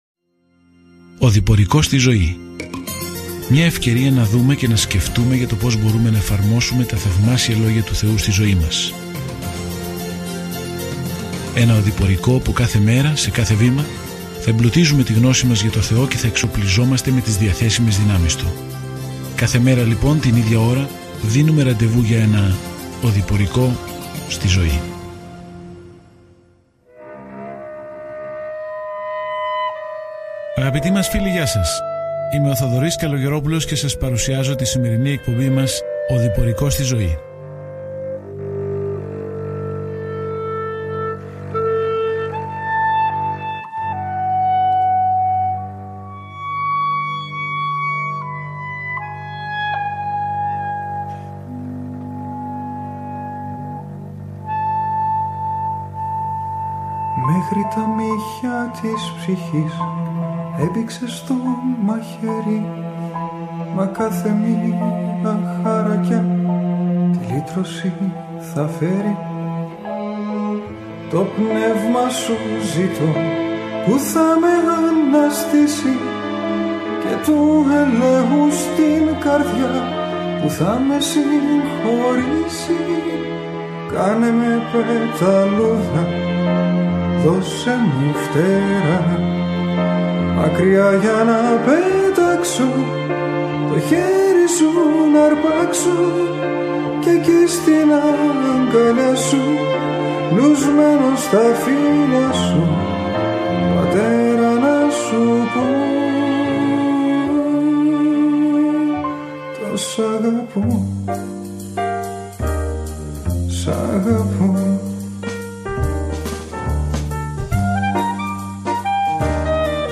There is an audio attachment for this devotional.
Καθημερινά ταξιδεύετε στον Ιωάννη καθώς ακούτε την ηχητική μελέτη και διαβάζετε επιλεγμένους στίχους από τον λόγο του Θεού.